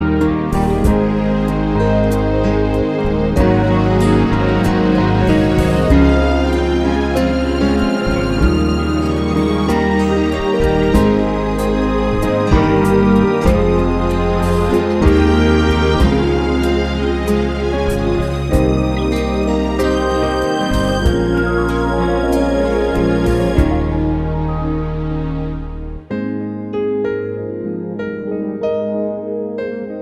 No Guitars Duets 4:20 Buy £1.50